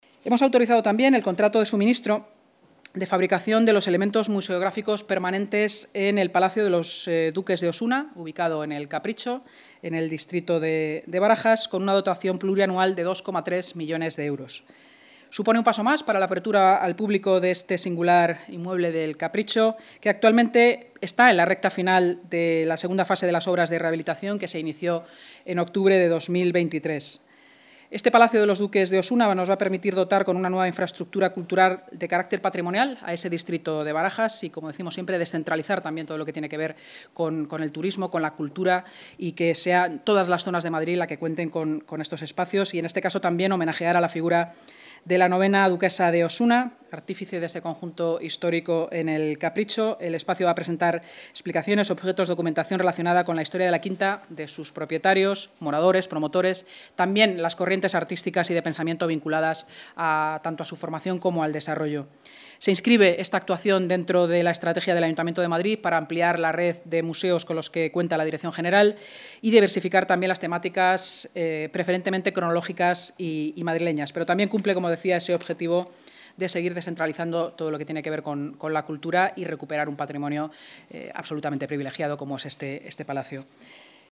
Nueva ventana:Intervención de la vicealcaldesa y portavoz municipal, Inma Sanz, en la rueda de prensa posterior a la Junta de Gobierno